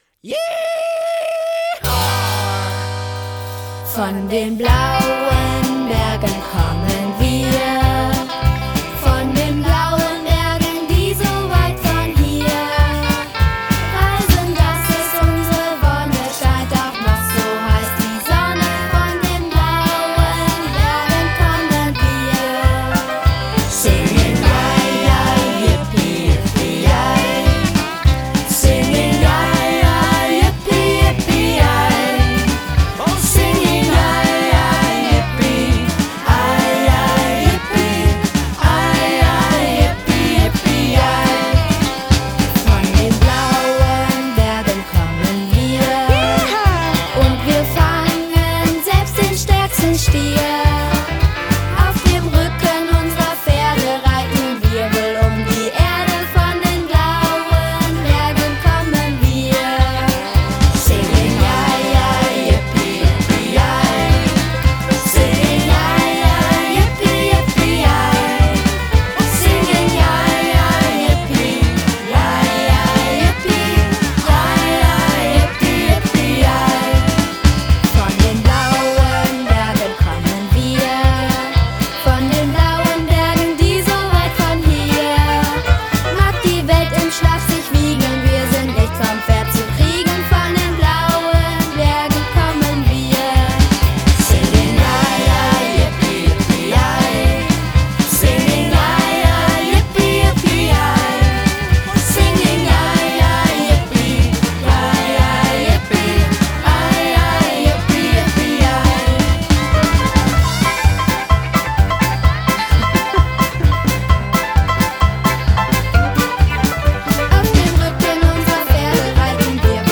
Kinderparty